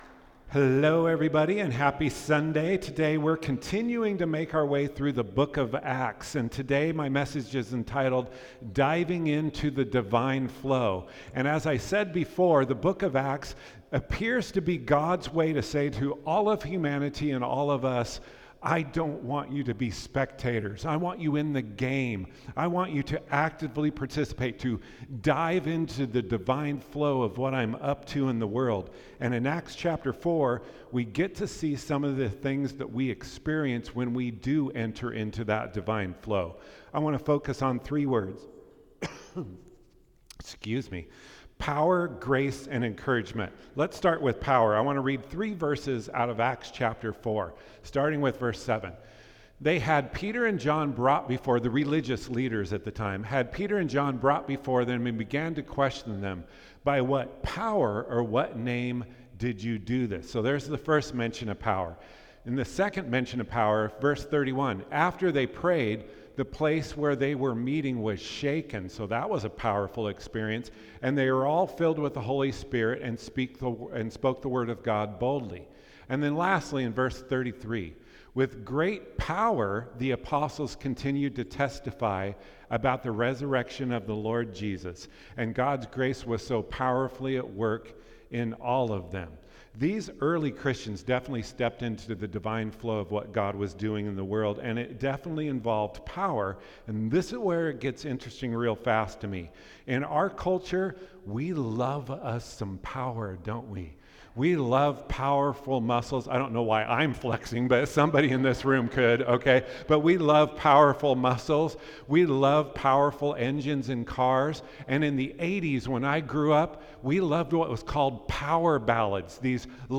Sermons | Faith Avenue Church